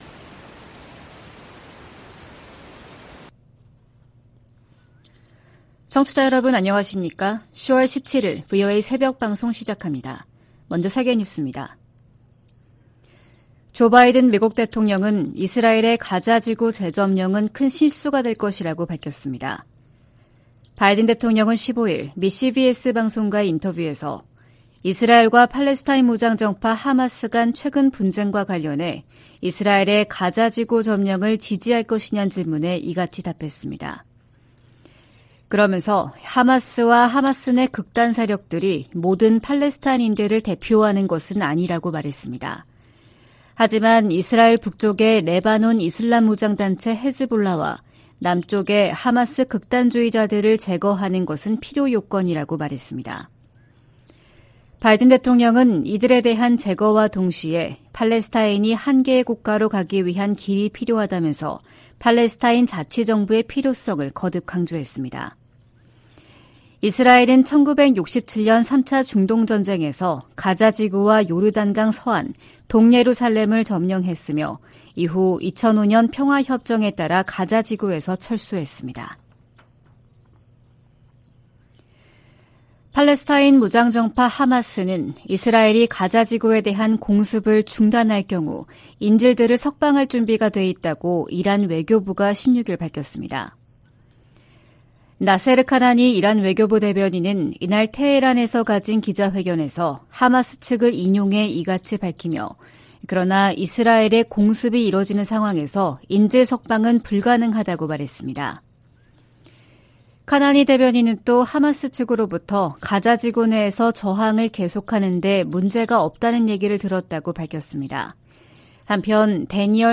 VOA 한국어 '출발 뉴스 쇼', 2023년 10월 17일 방송입니다. 북한이 지난달 컨테이너 1천개 분량의 군사장비와 탄약을 러시아에 제공했다고 백악관이 밝혔습니다. 미국 정부가 북러 무기 거래 현장으로 지목한 항구에서 계속 선박과 컨테이너의 움직임이 포착되고 있습니다. 줄리 터너 미 국무부 북한인권특사가 한국을 방문해 북한 인권 상황을 개선하기 위해 국제사회가 힘을 합쳐야 한다고 강조했습니다.